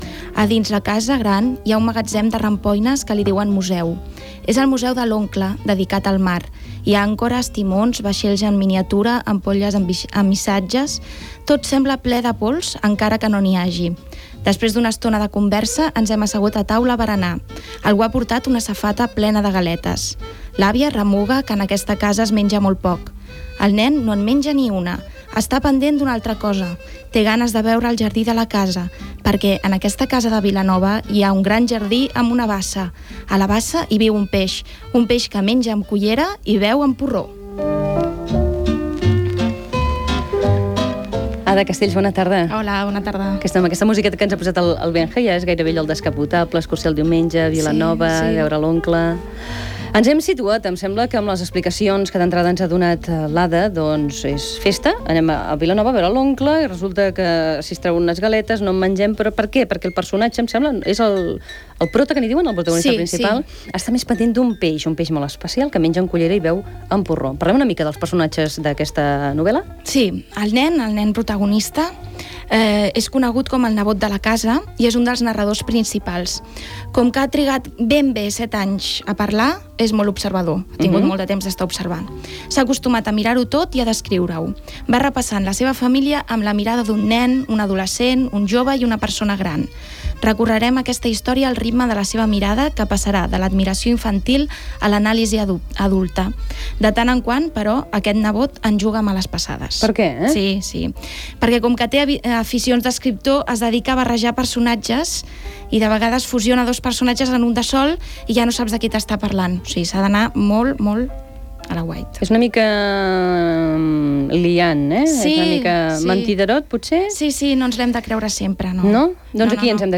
Lectura d'un fragment de la novel·la "Mon oncle" de Màrius Serra i comentari sobre aquesta obra. Pregunta sobre un moment de l'obra i concurs. Trucades telefòniques
Entreteniment